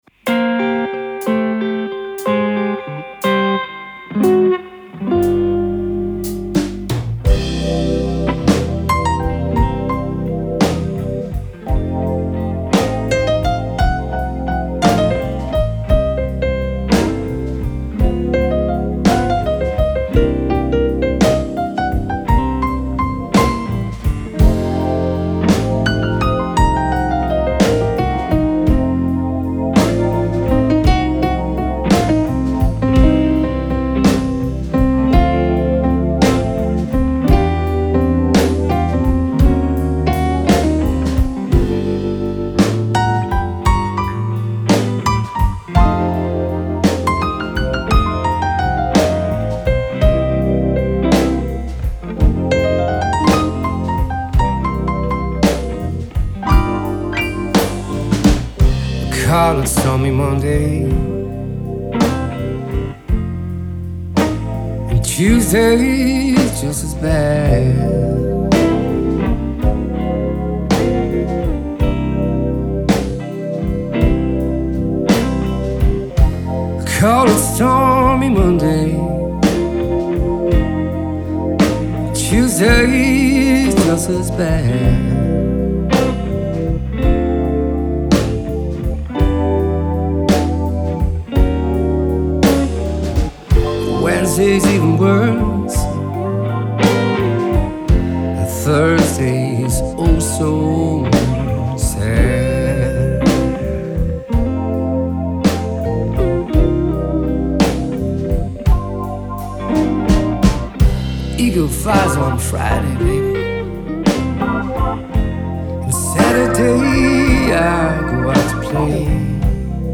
Blues & Rock